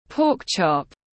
Sườn lợn tiếng anh gọi là pork chop, phiên âm tiếng anh đọc là /pɔːk ʧɒp/
Pork chop /pɔːk ʧɒp/